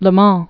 (ləɴ)